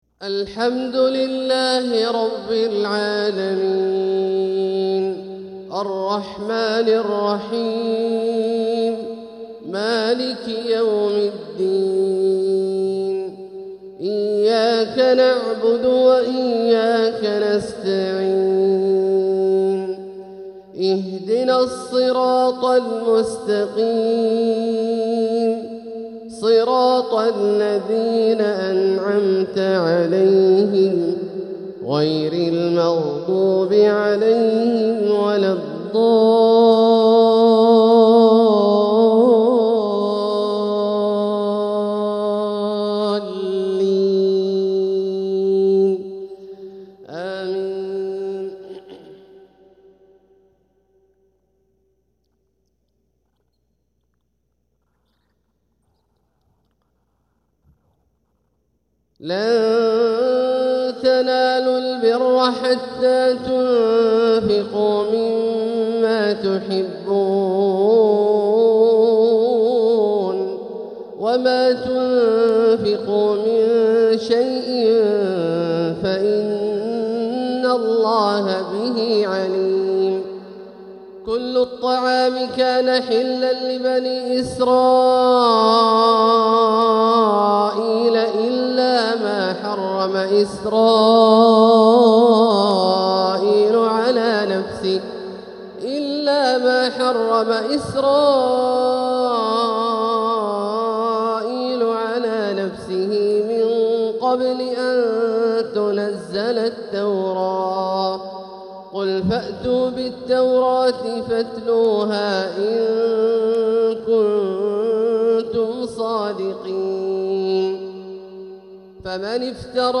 | Fajr prayer from Surah Aal-i-Imraan 3-6-2025 > 1446 > Prayers - Abdullah Al-Juhani Recitations